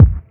Kick
Original creative-commons licensed sounds for DJ's and music producers, recorded with high quality studio microphones.
Low Kickdrum F Key 15.wav
dry-bass-drum-one-shot-f-key-156-hoB.wav